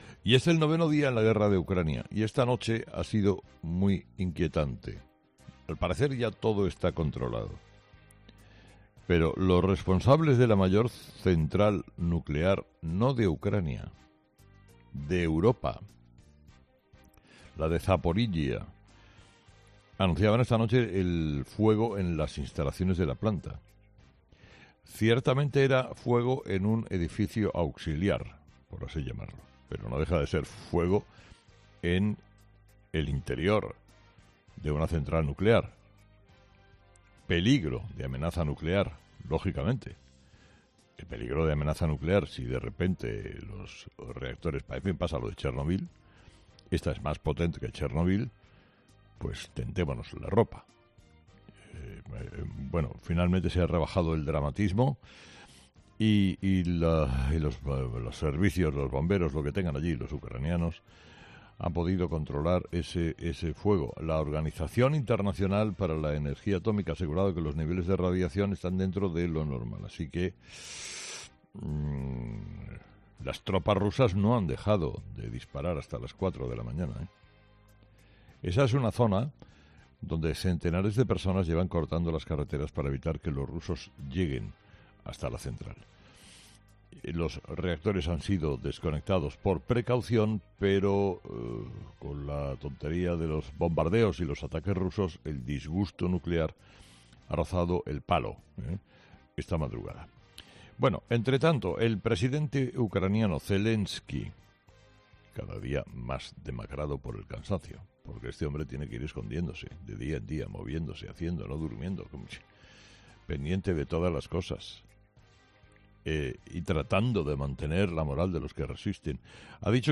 Carlos Herrera, director y presentador de 'Herrera en COPE', ha comenzado el programa de este jueves analizando las principales claves de la jornada, que pasan, entre otros asuntos, por la clasificación del Betis para la final de la Copa del Rey de fútbol y la última hora de la guerra en Ucrania.
El comunicador ha arrancado la mañana con el himno del Betis, su equipo, que ha logrado clasificarse para la final de la Copa del Rey de fútbol 17 años después.